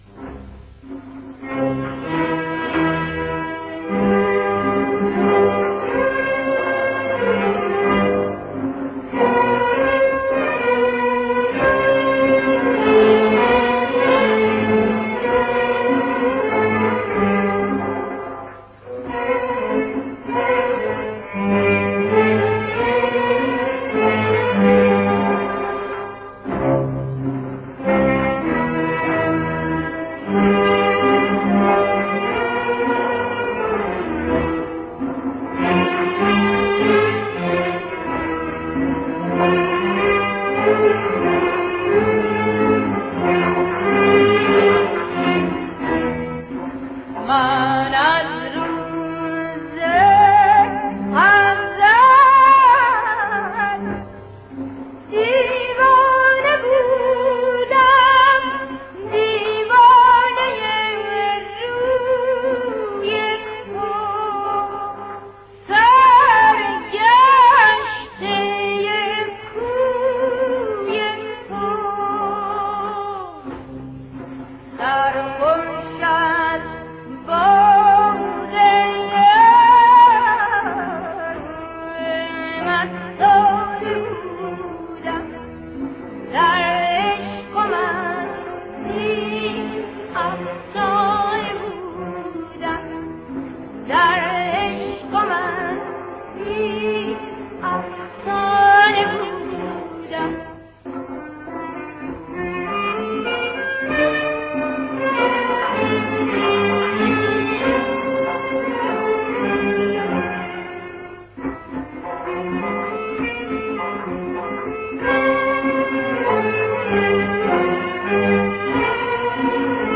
دستگاه: سه گاه